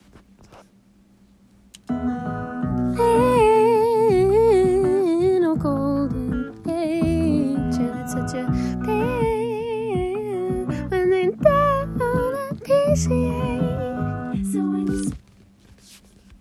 ME FIGURING OUT THE PRE MELODY